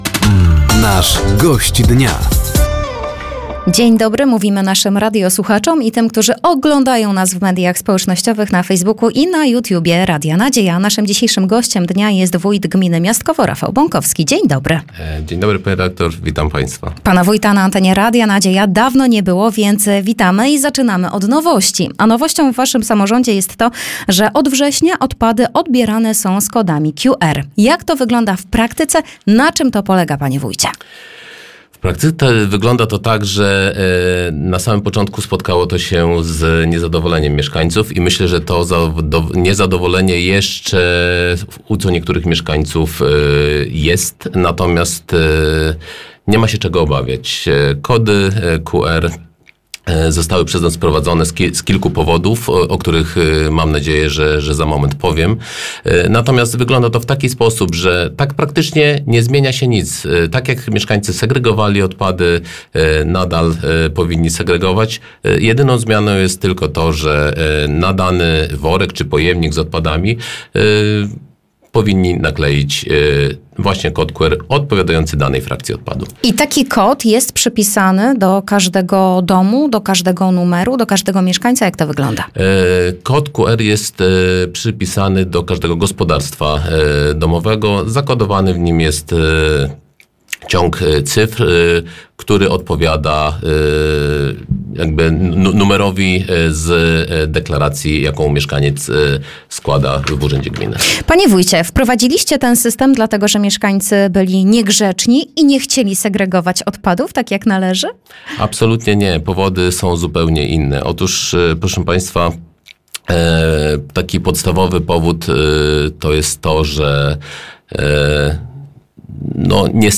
O sprawach ważnych dla mieszkańców mówił Rafał Bąkowski, wójt gminy Miastkowo.